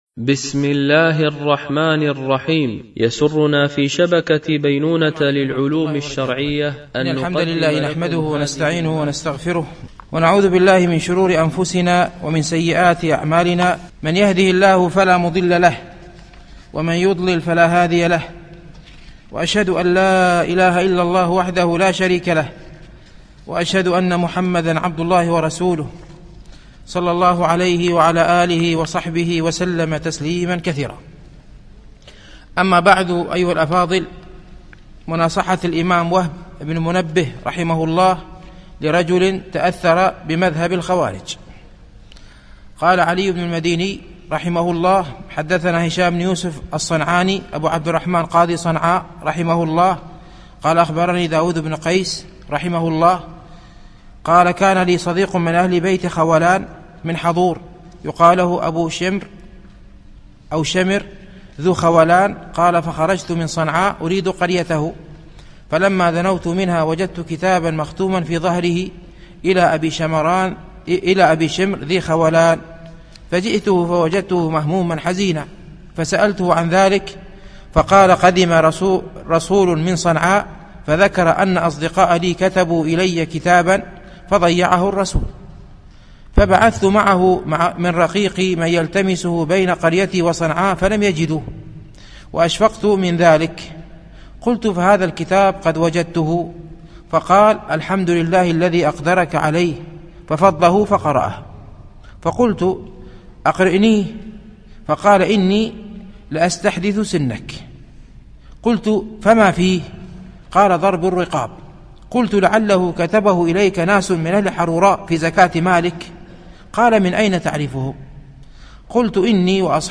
شرح رسالة وهب بن منبه في نصيحته لأحد الخوارج _ الدرس الثاني